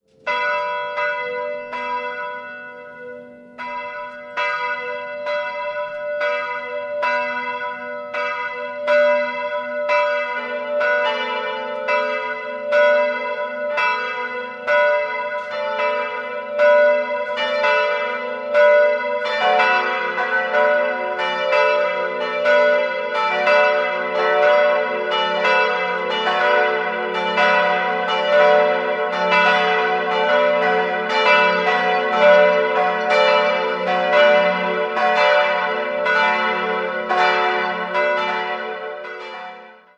Die drei stilistisch etwas ungewöhnlichen aber interessanten Altäre im Inneren überraschen den Besucher. 3-stimmiges TeDeum-Geläute: fis'-a'-h' Alle drei Glocken (844, 474 und 333 kg) wurden 1956 von Friedrich Wilhelm Schilling in Heidelberg gegossen.